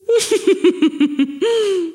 Mujer riendo 4
carcajada
mujer
risa
Sonidos: Voz humana